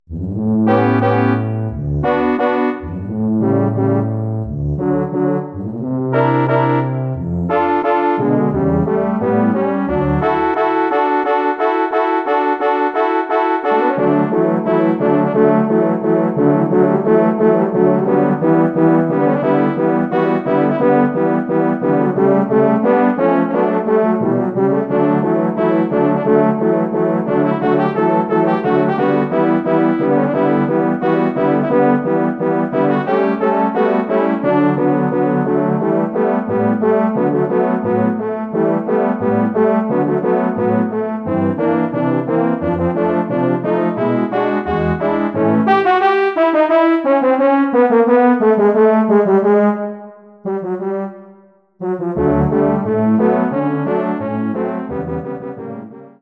Bearbeitung für Hornquartett
Besetzung: 4 Hörner
Arrangement for horn quartet
Instrumentation: 4 French horns